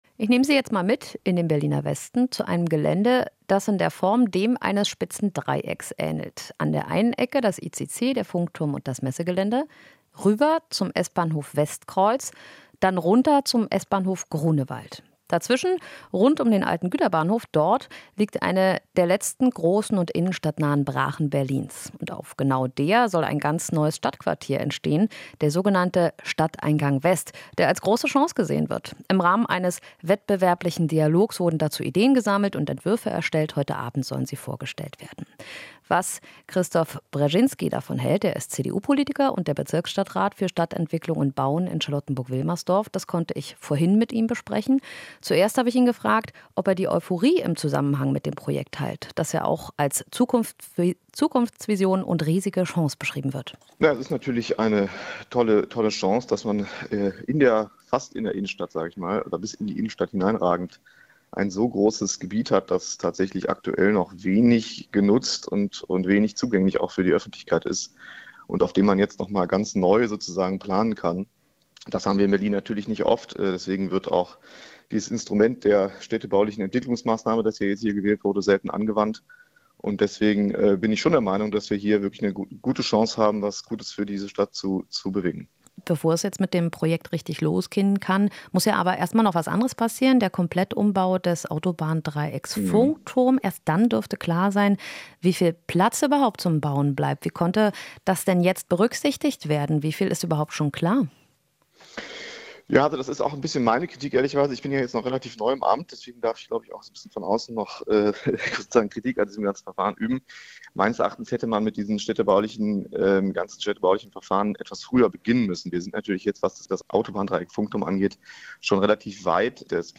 Inforadio Nachrichten, 29.07.2023, 18:00 Uhr - 29.07.2023